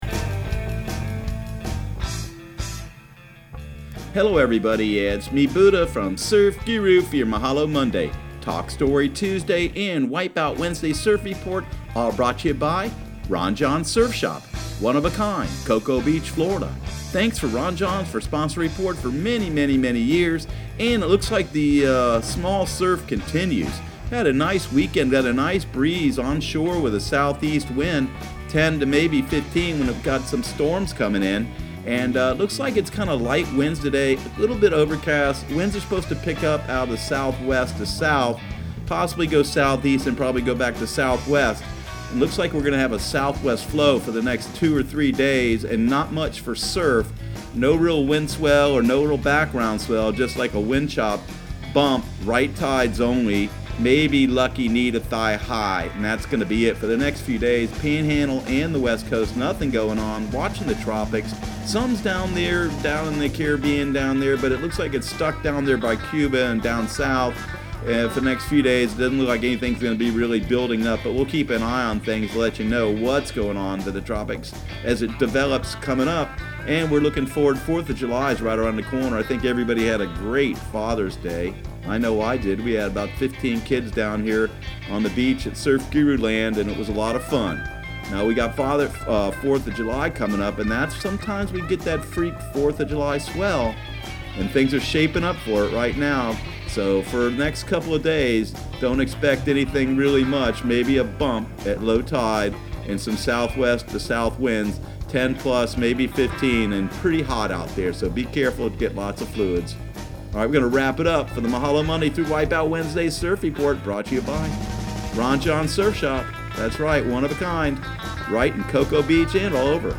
Surf Guru Surf Report and Forecast 06/17/2019 Audio surf report and surf forecast on June 17 for Central Florida and the Southeast.